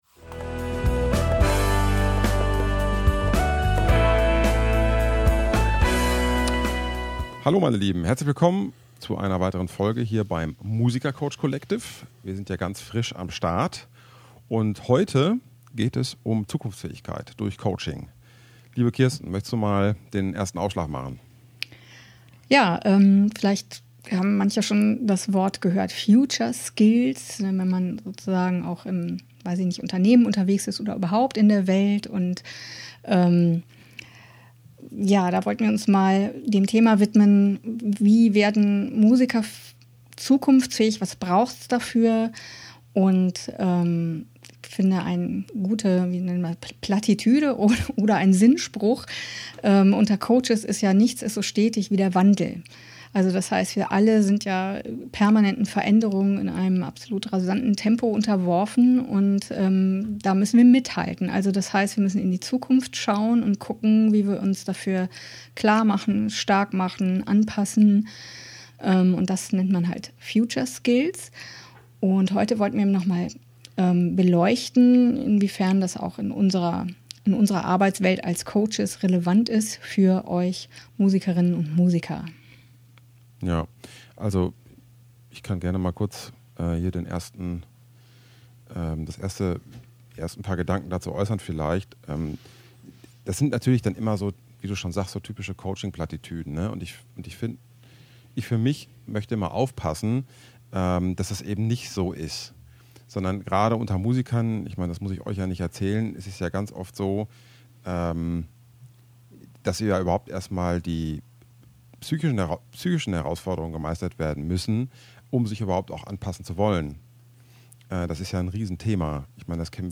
Future Skills durch Coaching II Talk